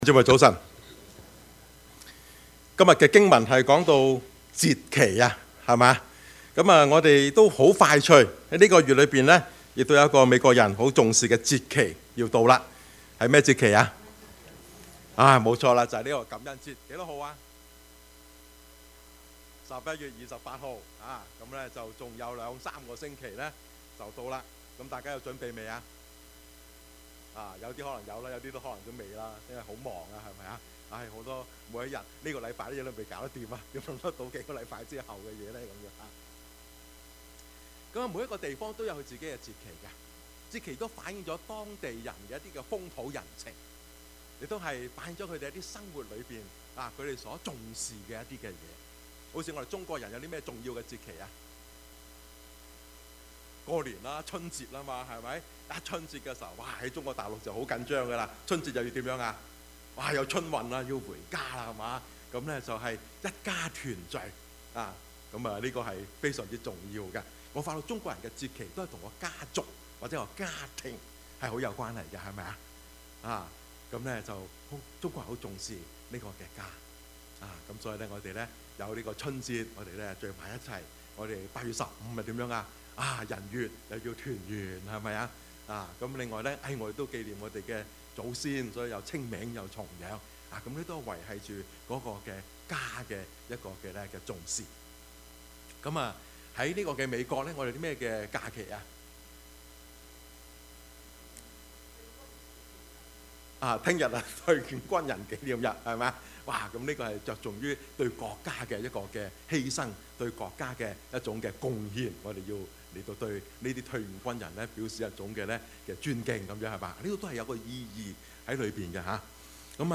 Service Type: 主日崇拜
Topics: 主日證道 « 每週一字之「相」二 富貴不能淫 »